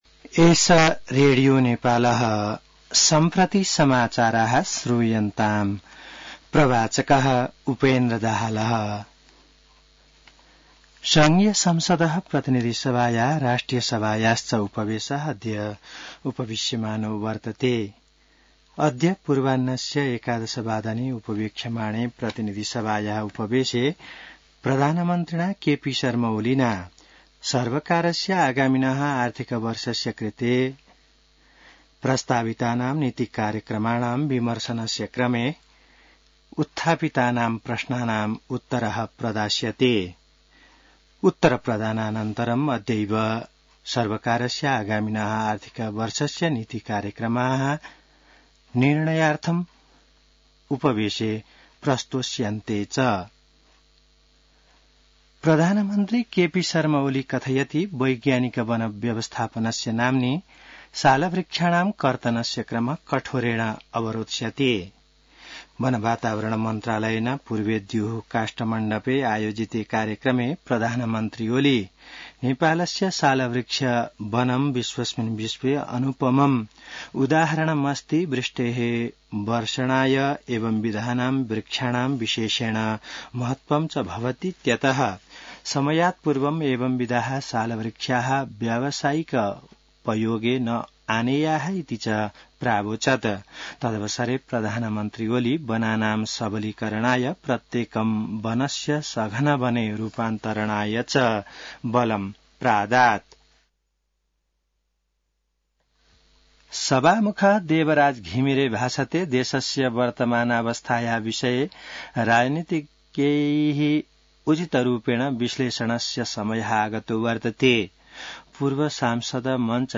संस्कृत समाचार : २८ वैशाख , २०८२